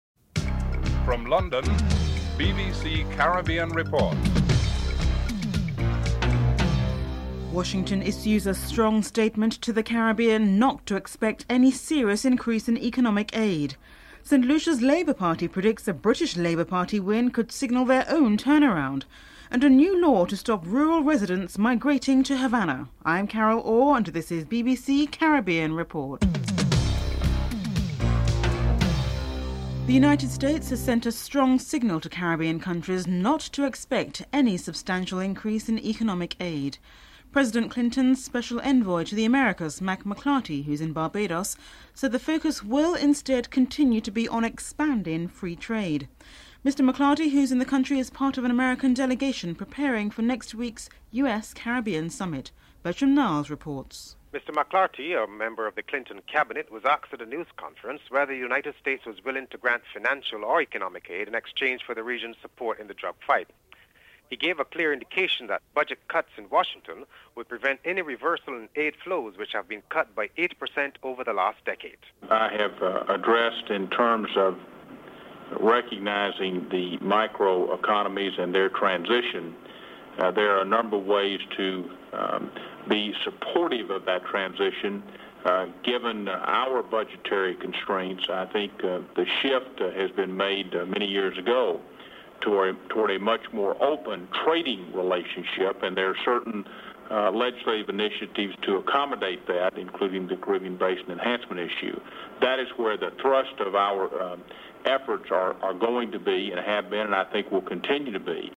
1. Headlines (00:00-00:29)
President Clinton's Special Envoy to the Americas, Mr Mack McLarty and Prime Minister of Barbados, Owen Arthur is interviewed (00:03-03:24)
Prime Minister of Jamaica, P.J. Patterson is interviewed (03:25-05:48)